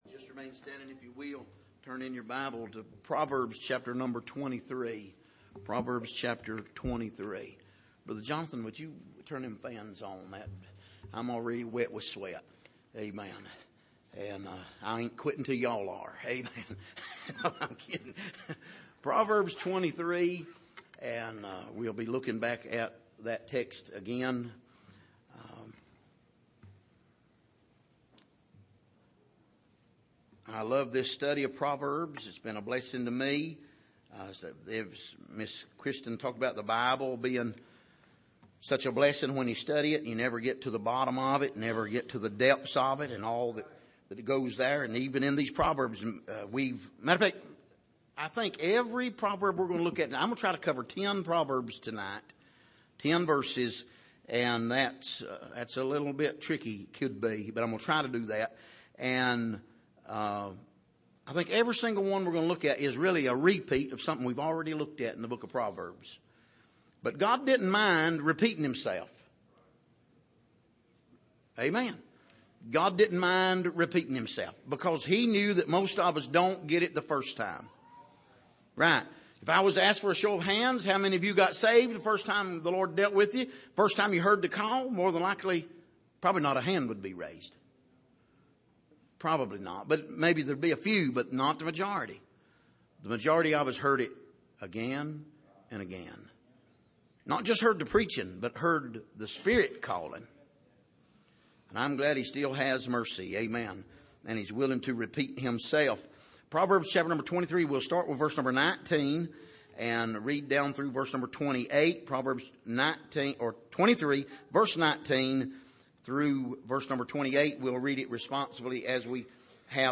Passage: Proverbs 23:19-28 Service: Sunday Evening